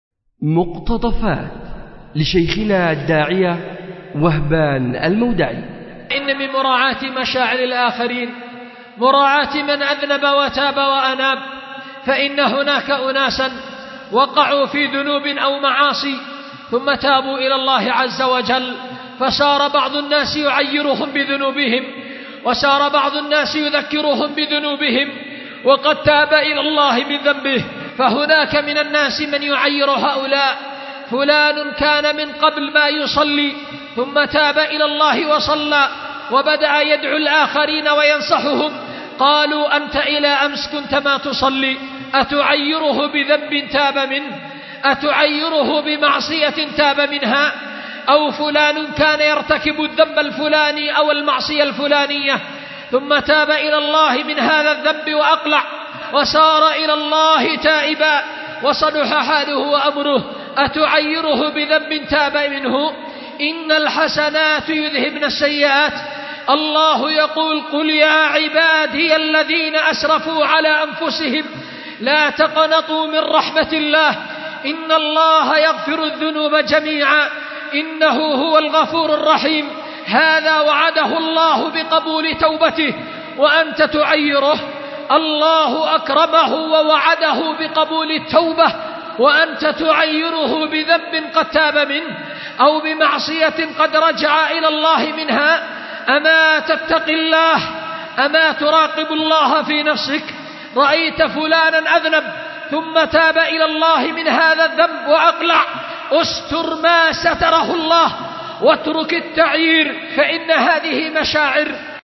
مقتطف من خطبة